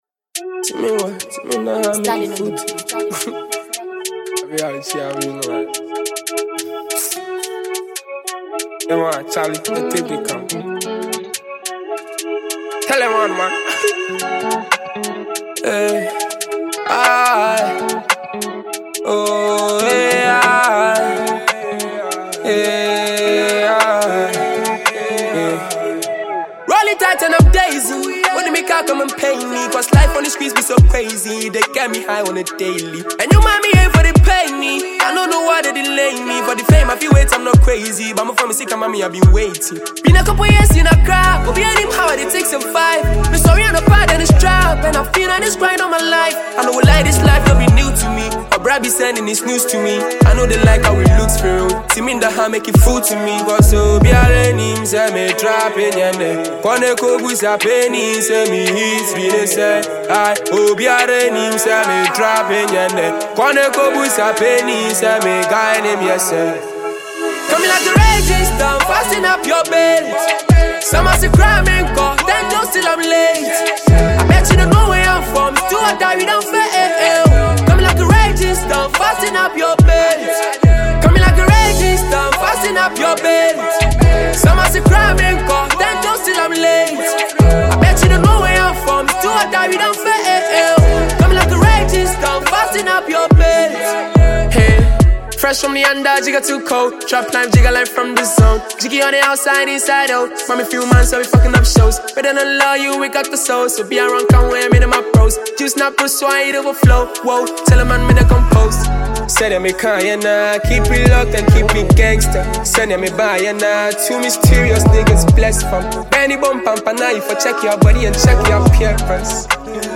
Ghana MusicMusic
Sensational Ghanaian singer
freestyle song